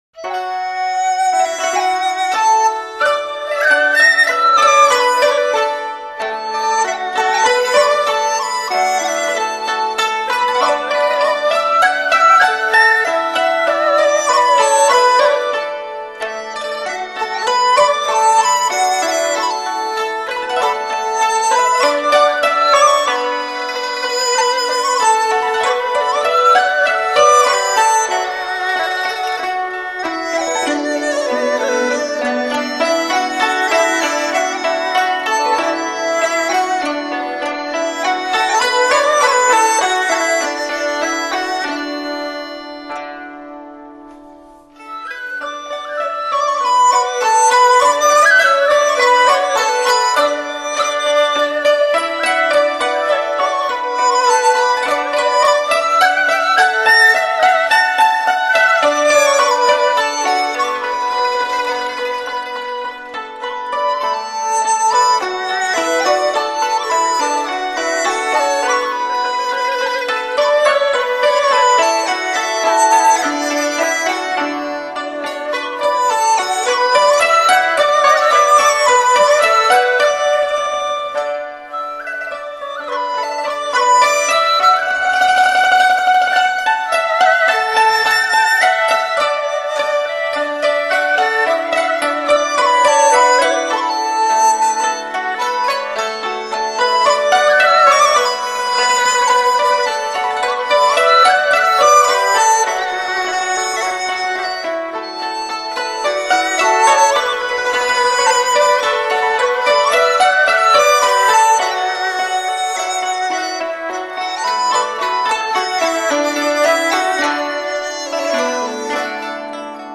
中国民乐大师合奏发烧天碟
录音细腻考究，音质清晰立体，音色柔和优美，旋律舒缓秀雅。
如画美景，沁人心脾，空灵旋律，净化心灵！
伴随着丝弦与竹管乐器演奏出的轻柔、舒缓的旋律．